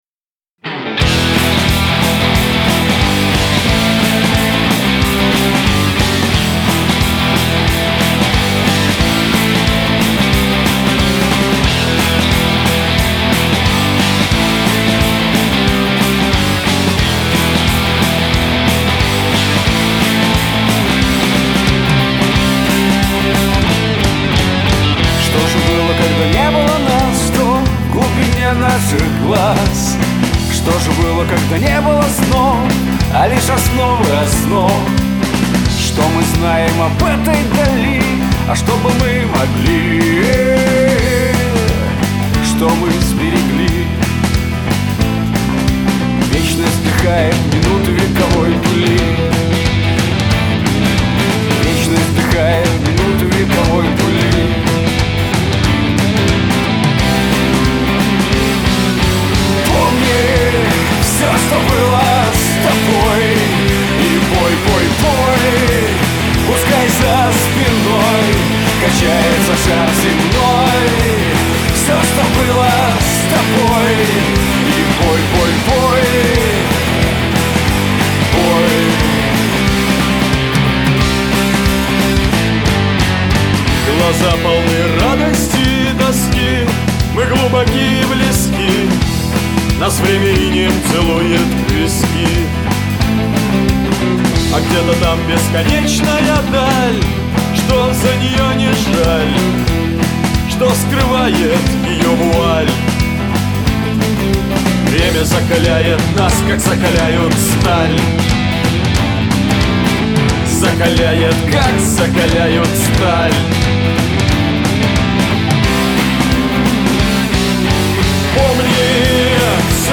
Качество: 192 kbps, stereo
Поп музыка, Рэп, Русские треки, 2026